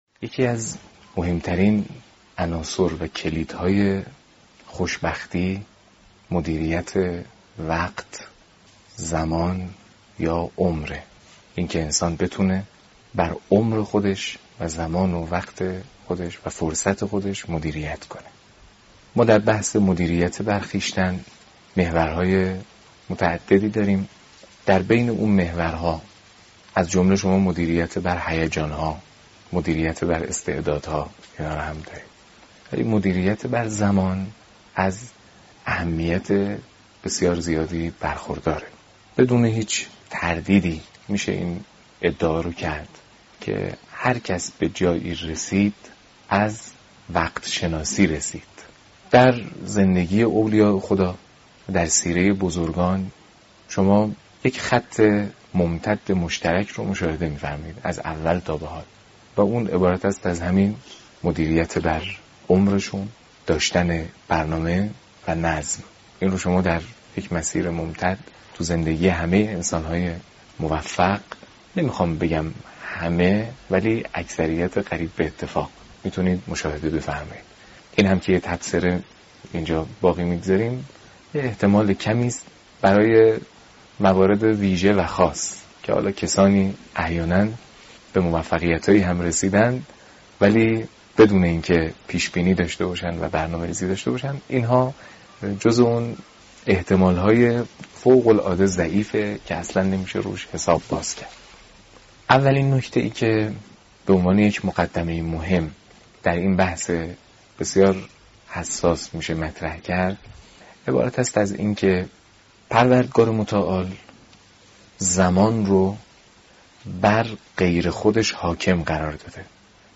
در این بخش، اولین جلسه از بیانات حجت الاسلام محمدجواد حاج علی اکبری با عنوان «مدیریت زمان» را با مخاطبین گرامی به اشتراک می گذاریم.